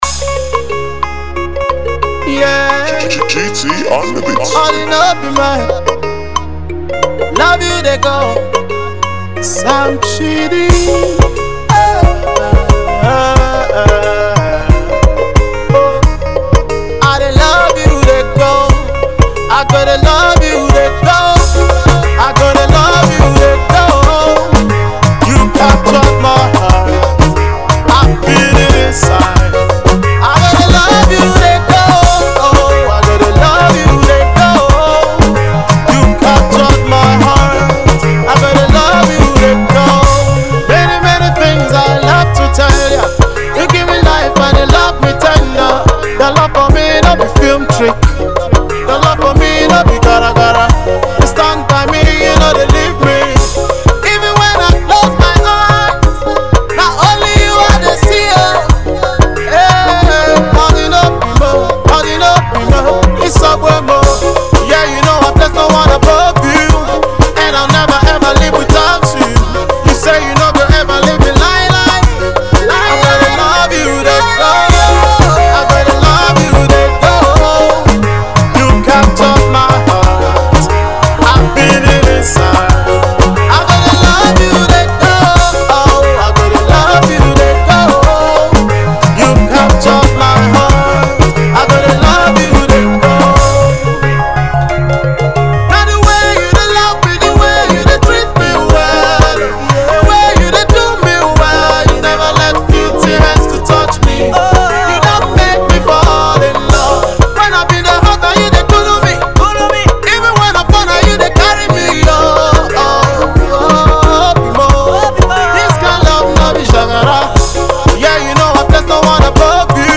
Nigerian Gospel musician